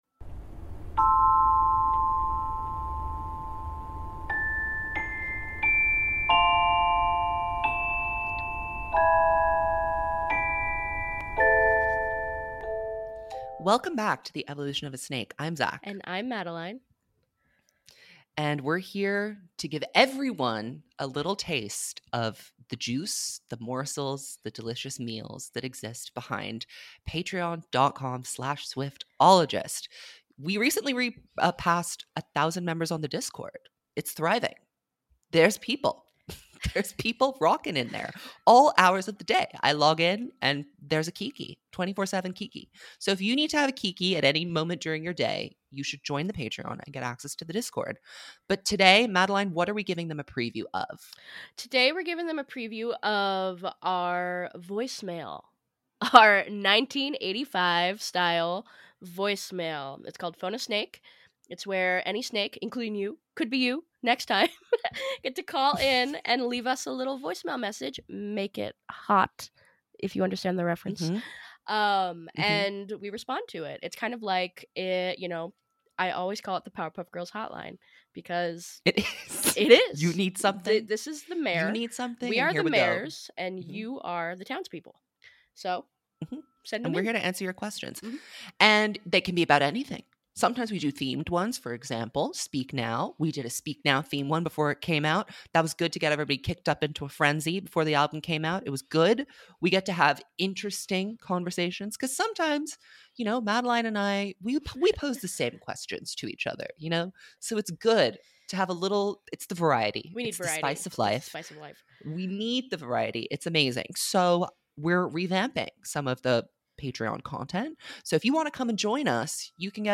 In this episode, we present the Snake Nation with a full-length preview of what lives behind the paywall. In our monthly voice mail segment, we answer your most pressing questions about happenings in the Taylor Nation and the world of pop culture.